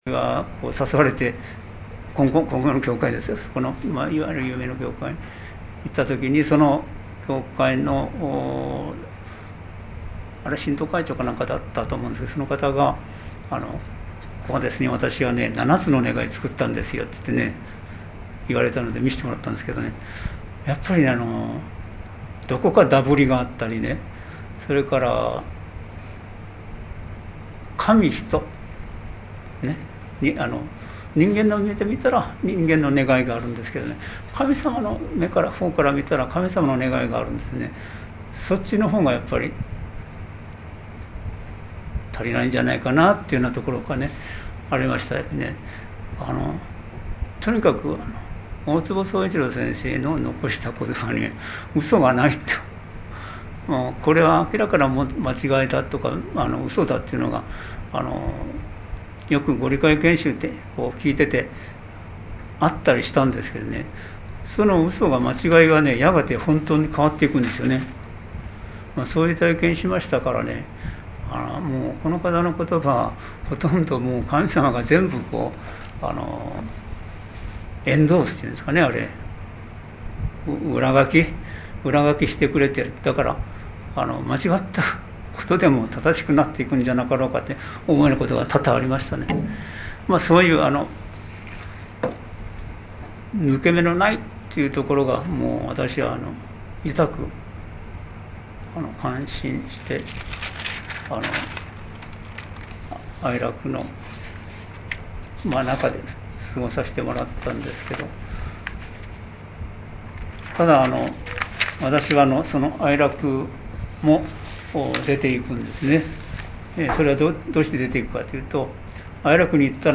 生神金光大神大祭